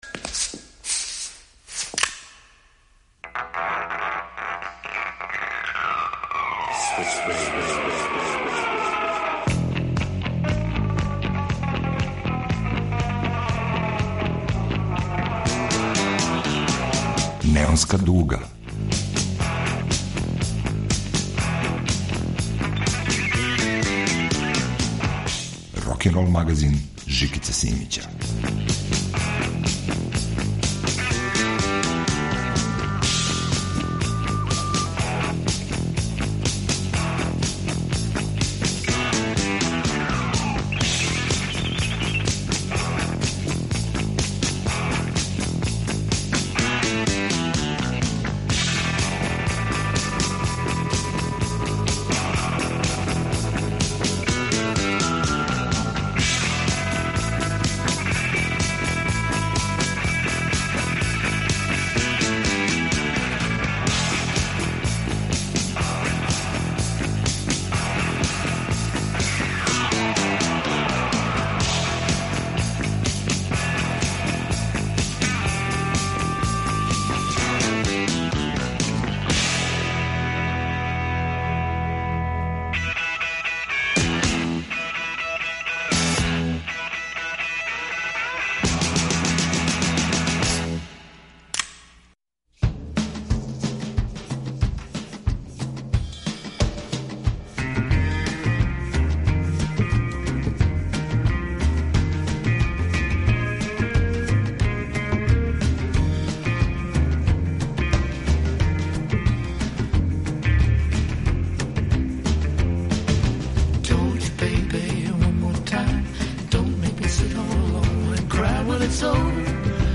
Нове, старе, домаће и стране песме су на репертоару.
Неонска дуга - рокенрол магазин